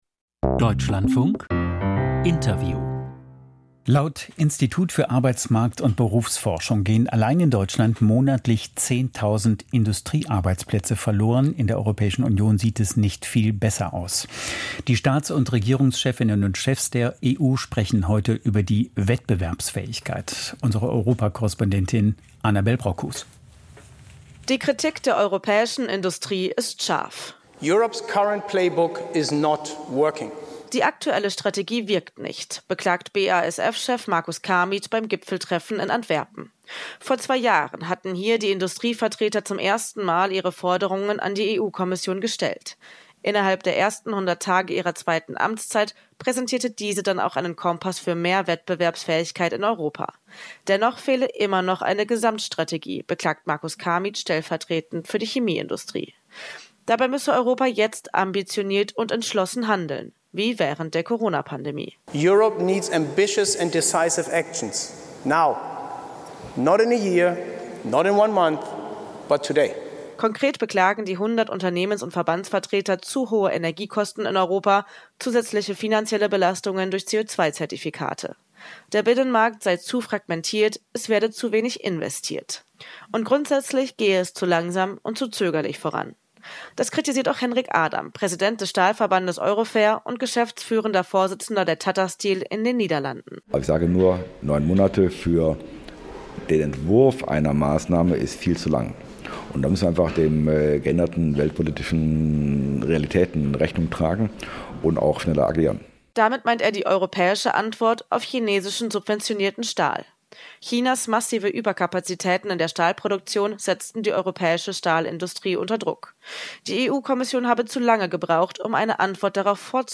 EU-Wettbewerbsfähigkeit: Interview EU-Wirtschaftskommissar Valdis Dombrovskis